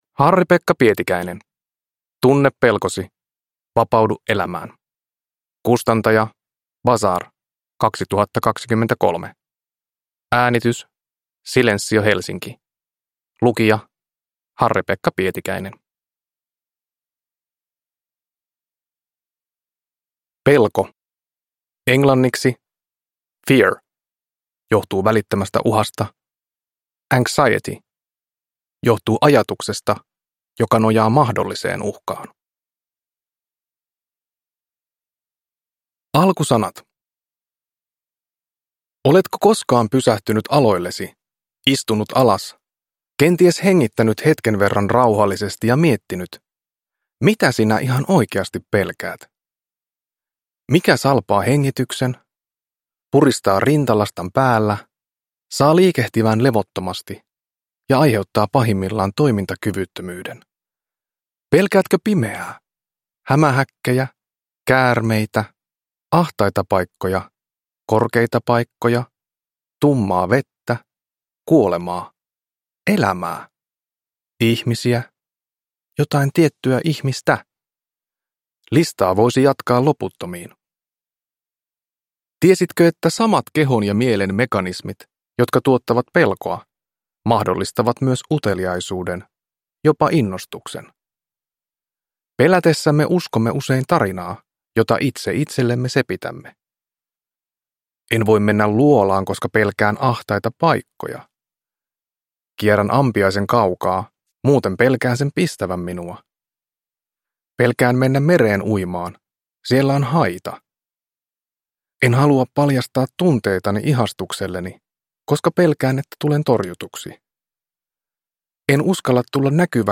Tunne pelkosi, vapaudu elämään – Ljudbok – Laddas ner